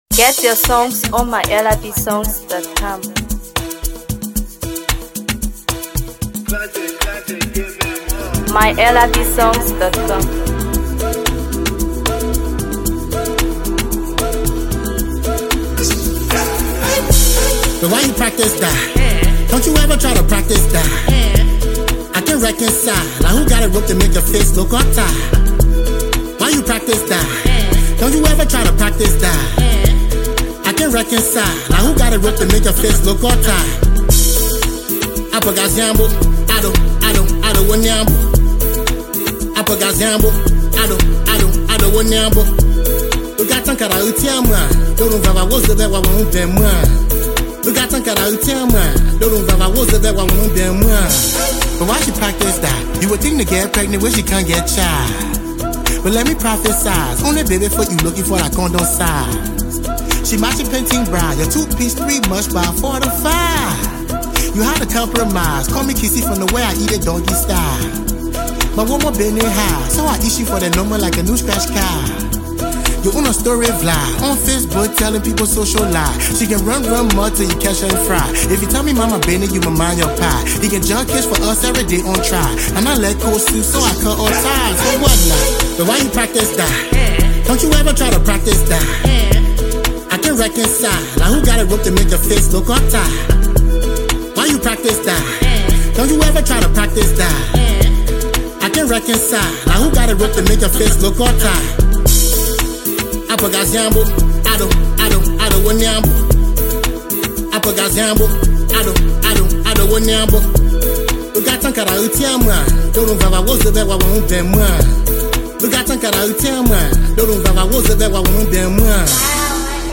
Afro PopMusic
With its infectious melody and captivating lyrics